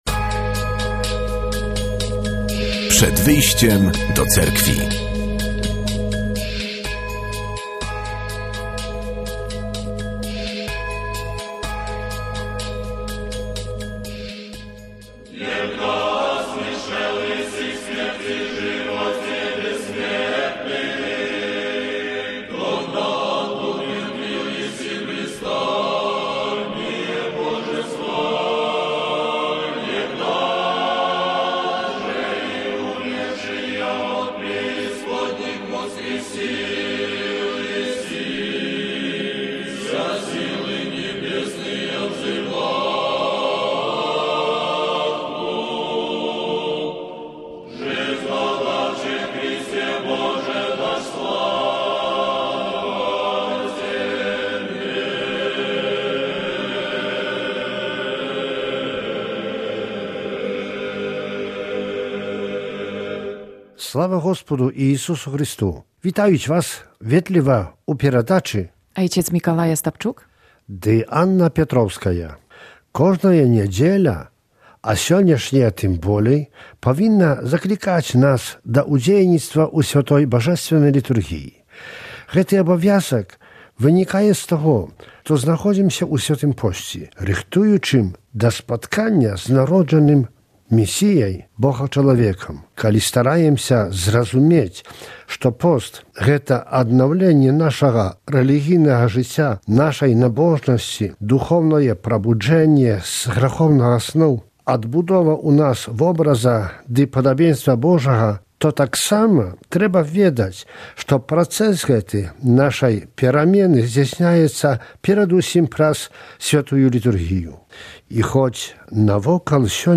Audycja wiernych Cerkwi prawosławnej.